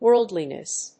/ˈwɝldlinʌs(米国英語), ˈwɜ:ldli:nʌs(英国英語)/